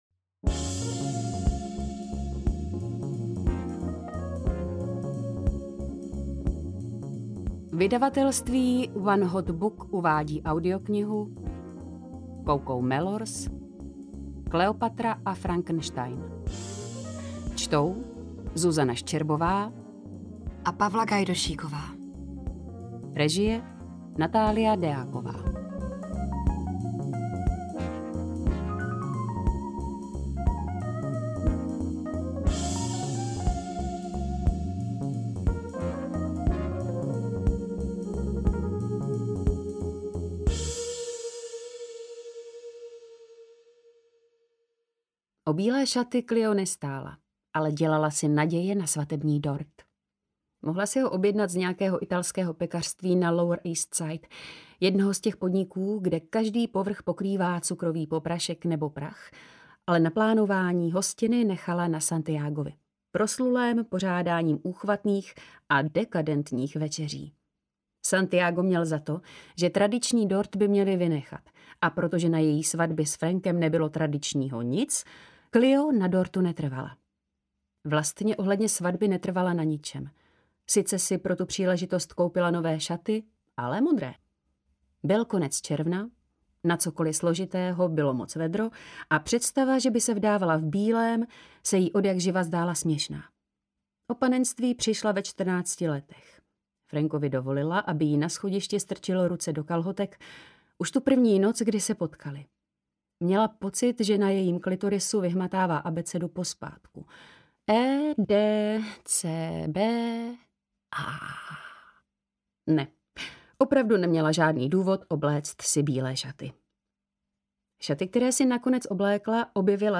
Kleopatra a Frankenstein audiokniha
Ukázka z knihy